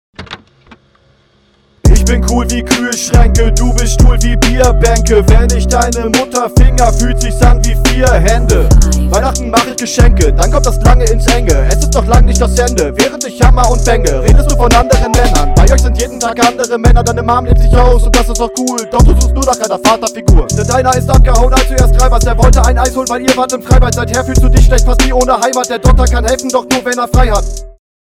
Sehr guter Flow, mit überraschend viel Flowvariation, für 28 Sekunden.